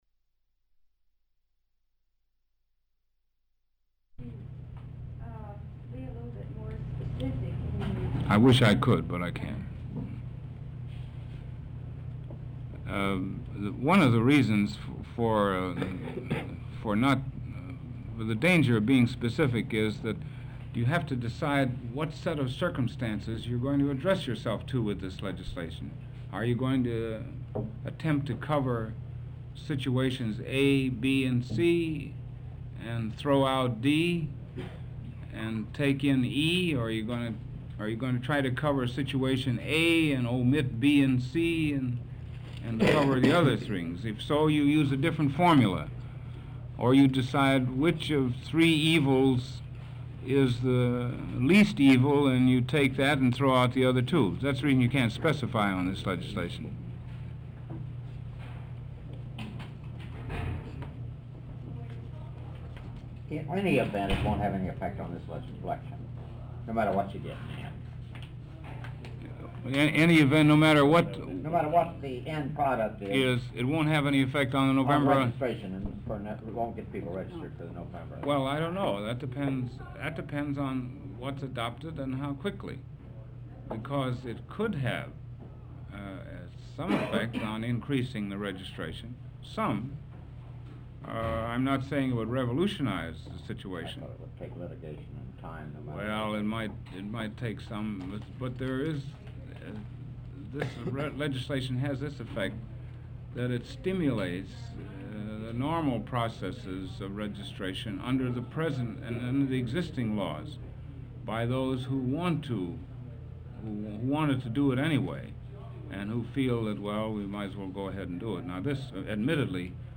Walter P. Reuther Digital Archive · Press Conference on Civil Rights Legislation, Tape 2 (of 2) · Omeka S Multi-Repository